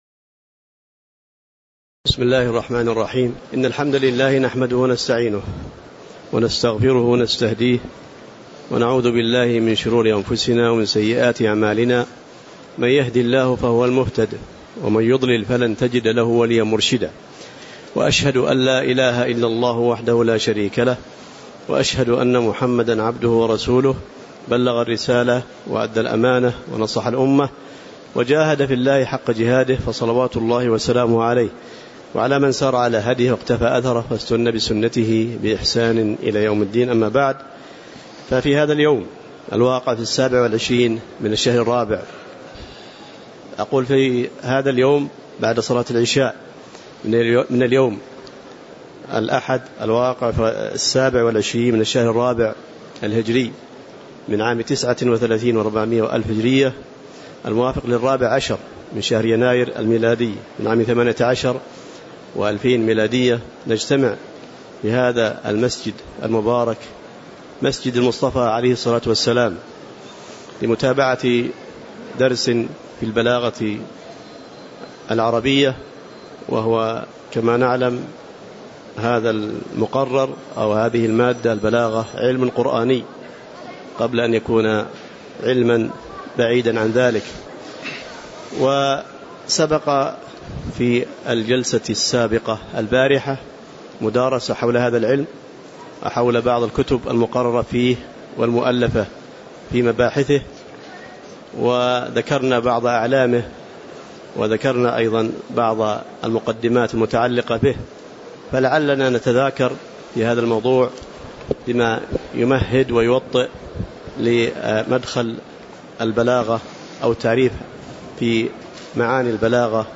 تاريخ النشر ٢٧ ربيع الثاني ١٤٣٩ هـ المكان: المسجد النبوي الشيخ